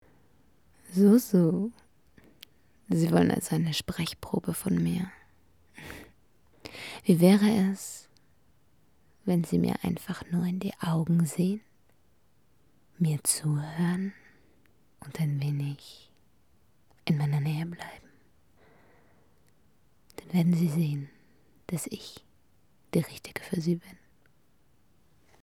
AW: [Freie Rollen: 11 M, 7 W] Wayne McLair - Der Meisterdieb sooo, hab mal wieder ins Blaue hinein improvisiert, nach wie vor bin ich aber auch gern bereit, ne Sprechprobe mit Originaltext abzugeben.